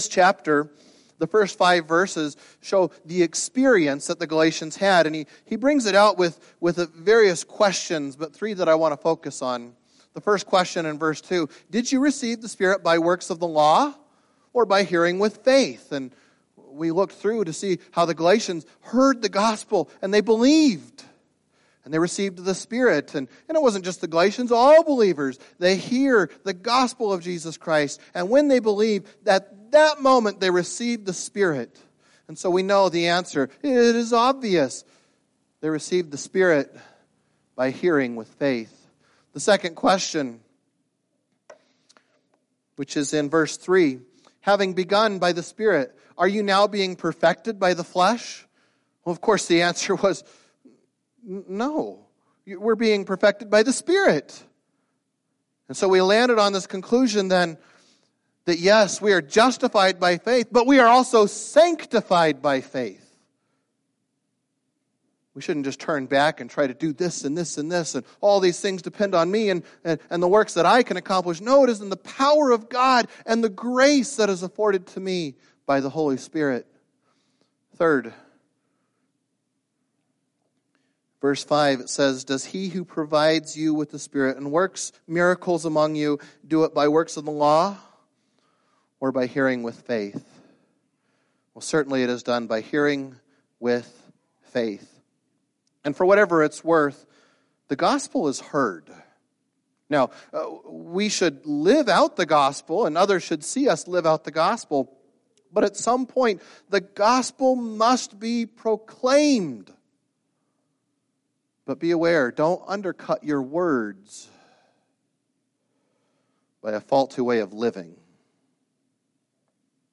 Past Sermons - Kuna Baptist Church